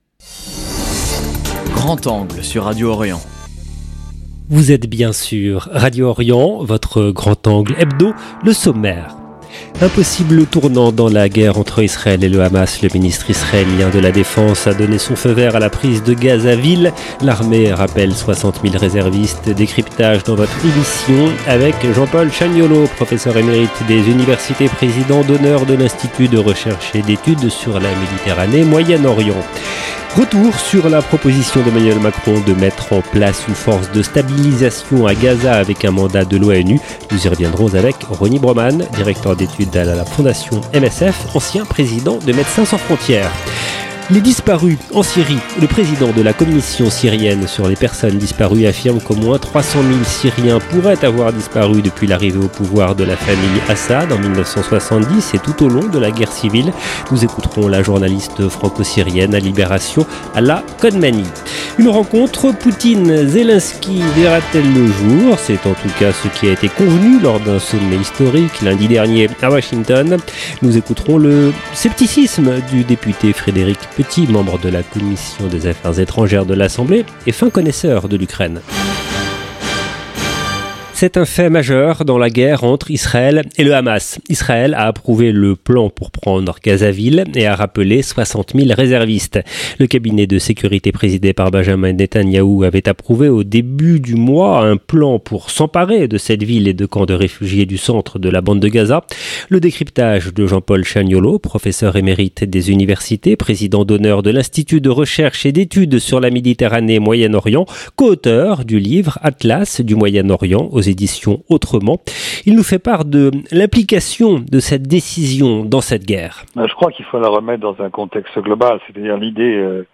Vous entendrez le scepticisme du député Frédéric Petit, membre de la commission des Affaires étrangères, fin connaisseur du dossier ukrainien. 0:00 10 min 18 sec